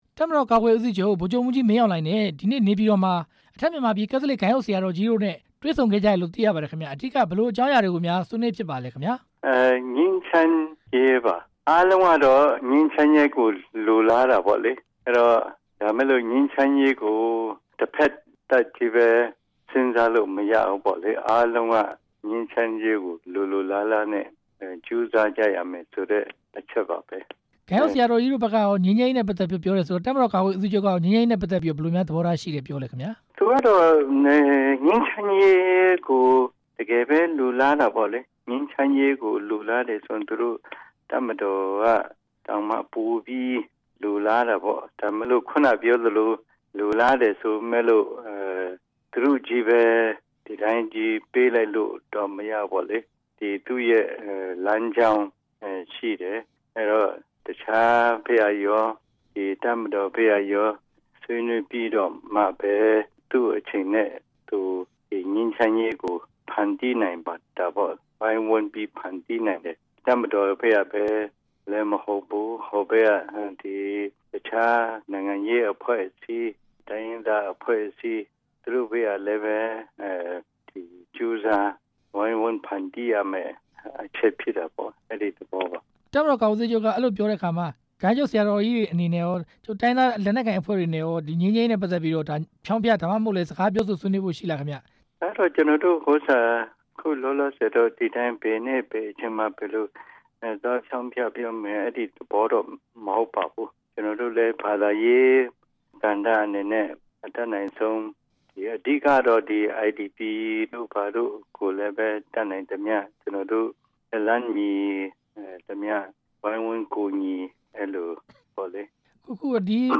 လားရှိုးမြို့ ကက်သလစ် ဂိုဏ်းအုပ်ဆရာတော်ကြီး ဖီးလစ်ဇဟောင်းနဲ့ ဆက်သွယ်မေးမြန်းချက်